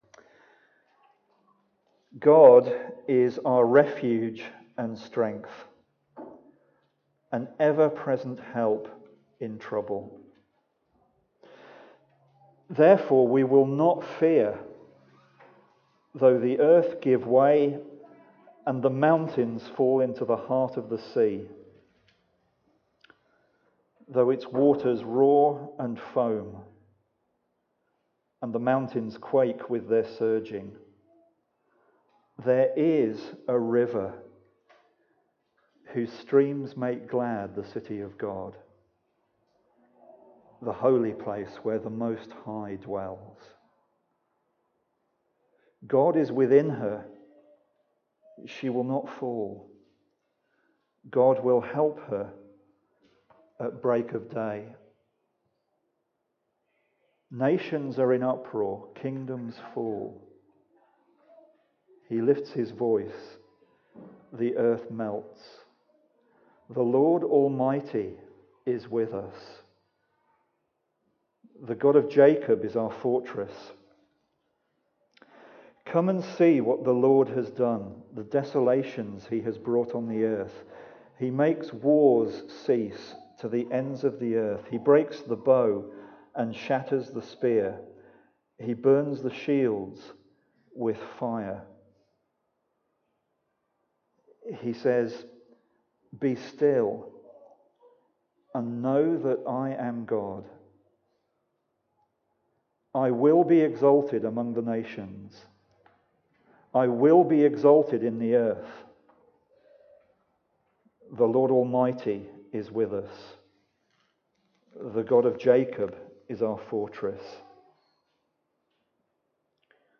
Preaching
God is our strength and refuge (Psalm 46) Recorded at Woodstock Road Baptist Church on 04 September 2016.